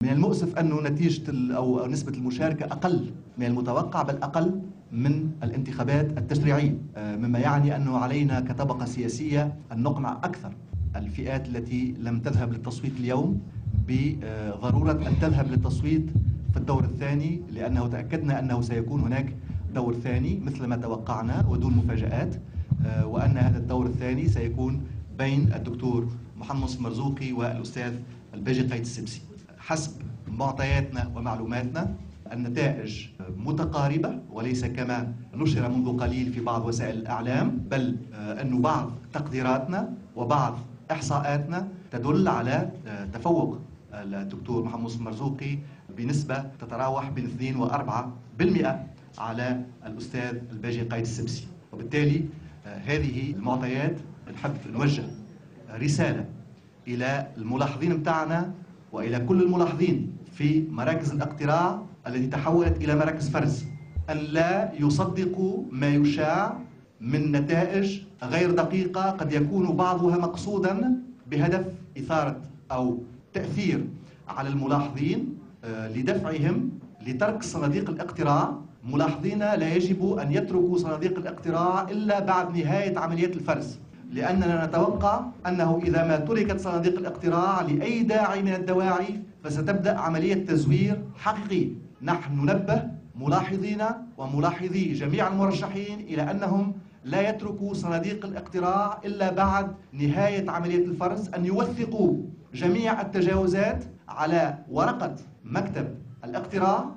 واعتبر عدنان منصر خلال ندوة صحفية عقدها اليوم الأحد أن نتائج الانتخابات الرئاسية كانت متوقعة ولم تحصل أي مفاجئات حيث تؤكد كل المؤشرات تأهل الباجي قائد السبسي لمنافسة محمد المنصف المرزوقي على كرسي الرئاسة في الدور الثاني.